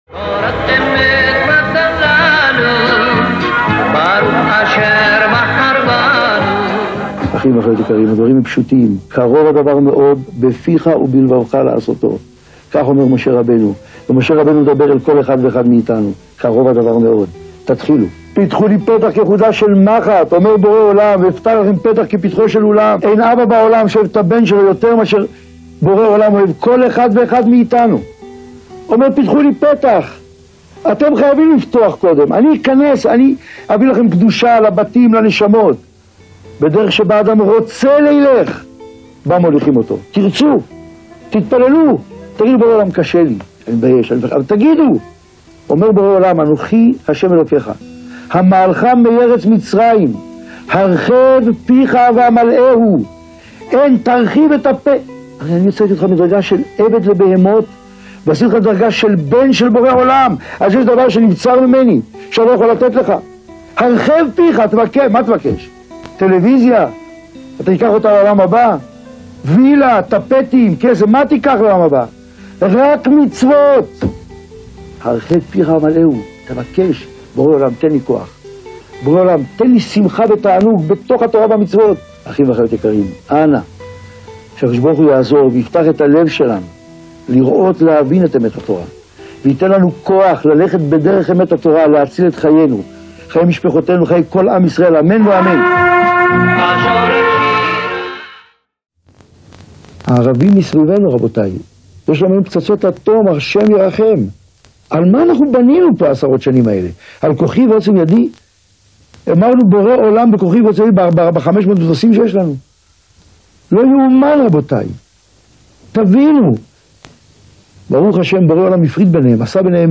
שעור תורה לזיכוי הרבים
_הרב אורי זוהר - 3 דרשות קצרות ועוצמתיות